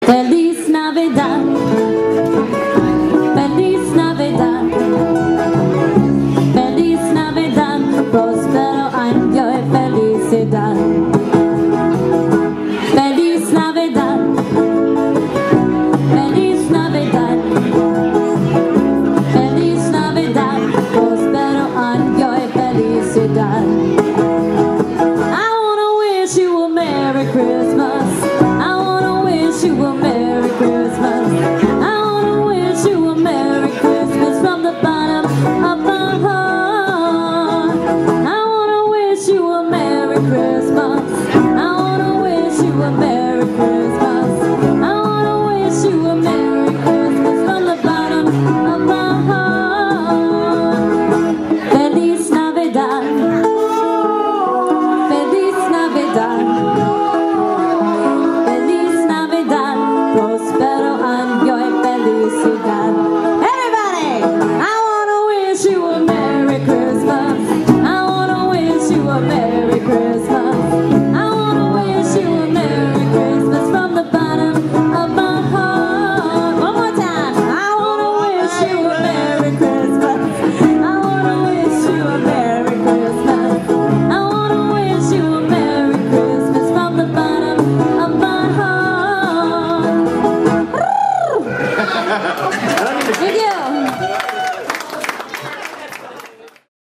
Recorded live at the Red Stallion December 10, 2005